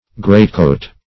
Greatcoat \Great"coat"\, n.
greatcoat.mp3